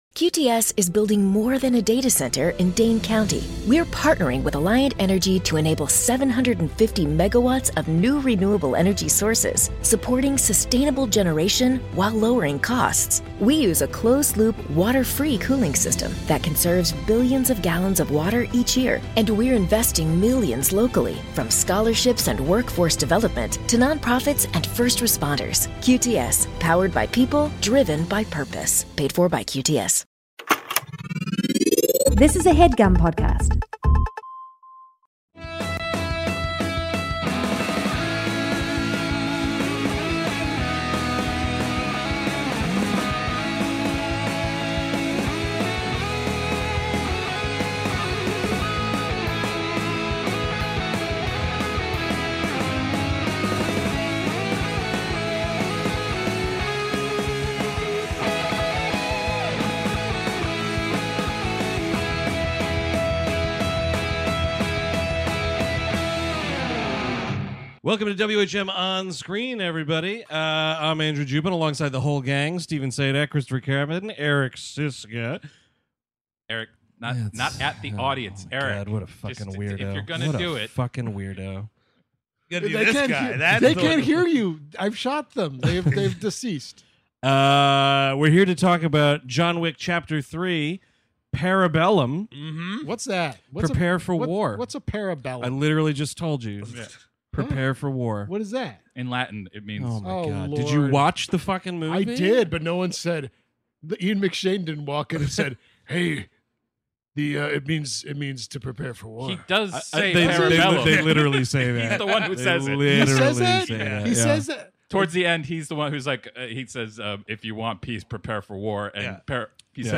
On this special WHM On-Screen, the guys are chatting about the latest installment in the incredibly lucrative, bullet-to-the-head franchise, John Wick: Chapter 3 - Parabellum!